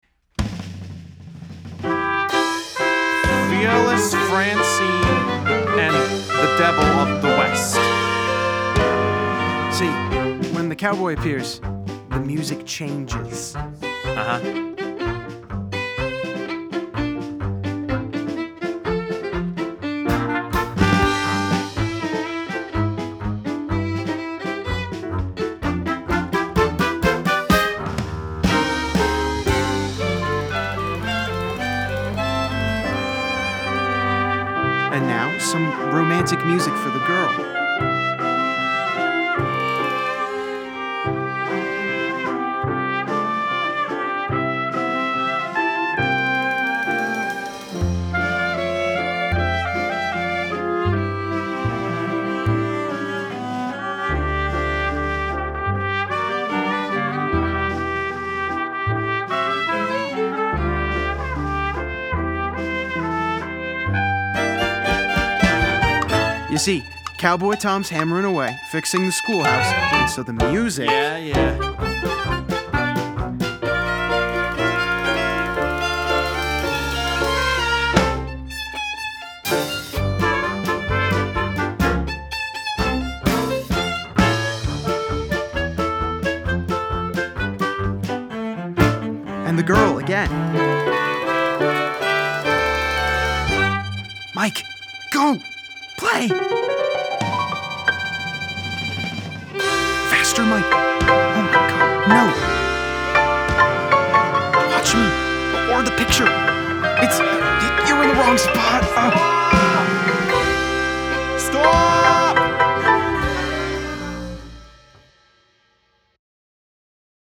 Demo recording
Vocals
Piano
Clarinet
Trumpet
Violin
Cello
Bass
Drums